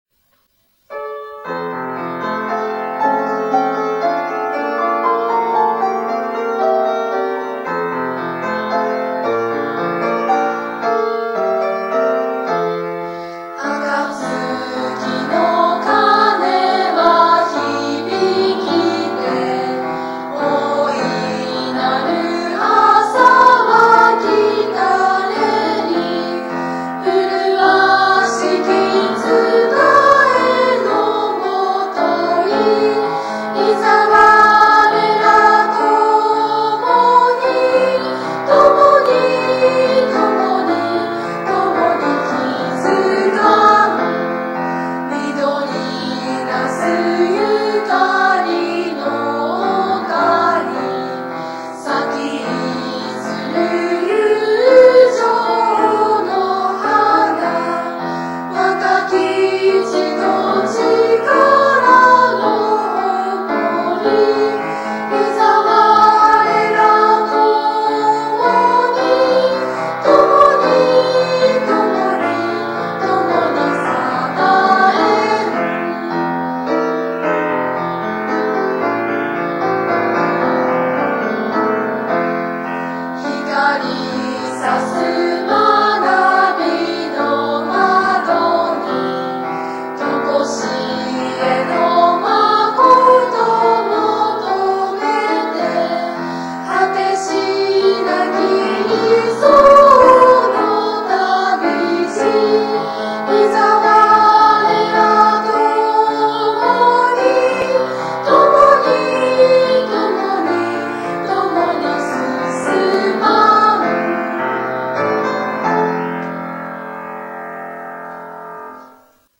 校歌は普通２拍子・４拍子の曲が多いのですが、この校歌は３拍子でできていて、固い日本語の歌詞を流れるように歌える曲になっています。